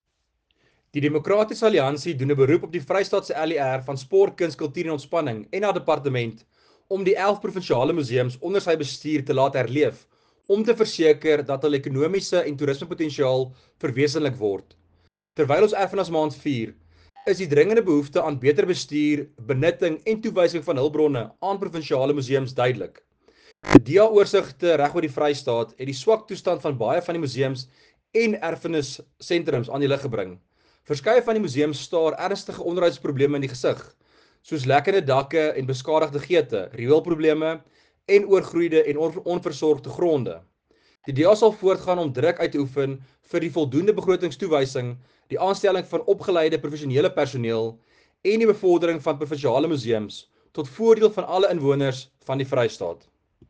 Afrikaans soundbites by Werner Pretorius MPL, and